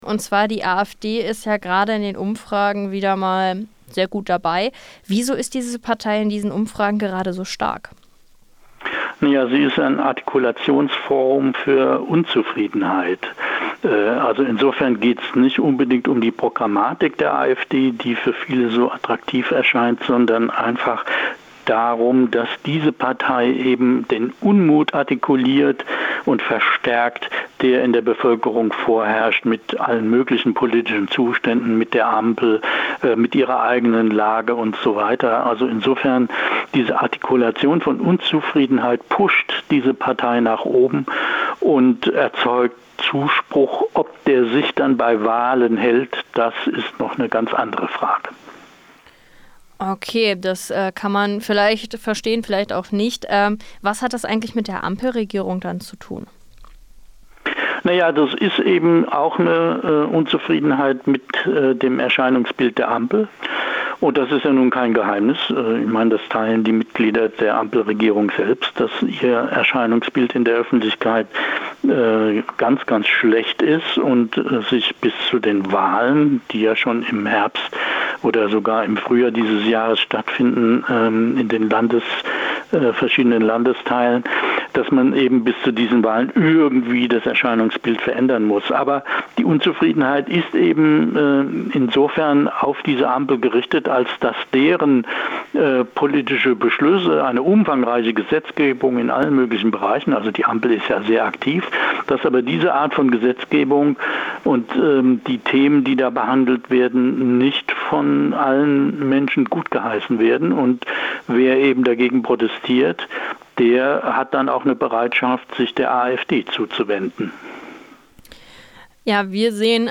Warum ist die AfD gerade so beliebt? - Interview